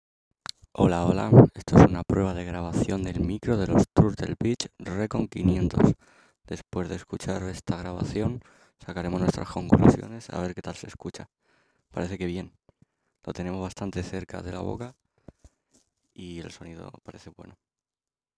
Prueba de Micro
Después de varias pruebas notamos que debería tener algún filtro anti-pop porque es bastante notable el ruido.
Aunque las voces las saca muy claras y limpias ese "pop" de vez en cuando lo afea un poco.
No hay ruidos ni grabaciones de sonidos de alrededor a menos que estén muy cercanos.
Grabacion-micro-Turtle-Beach-Recon-500-tecnolocura.mp3